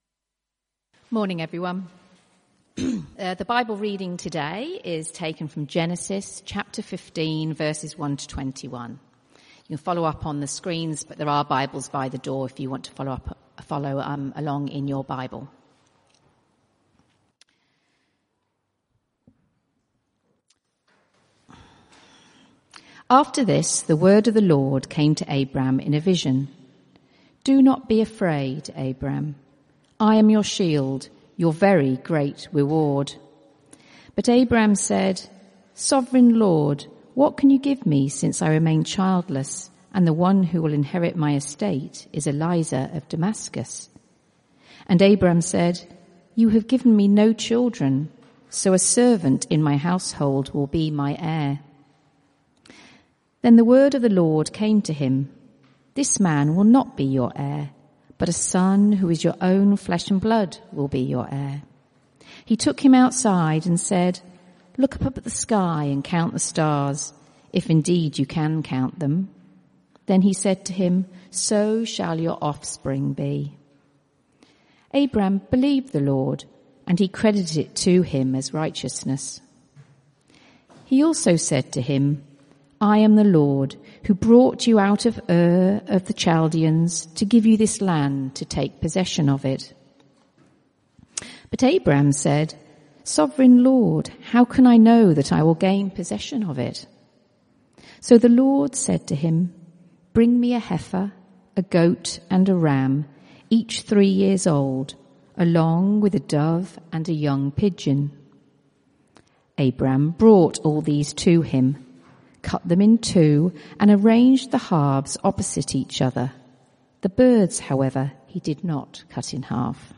CBC Service: 12 January 2025 Series
Type: Sermons